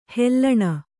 ♪ hellaṇa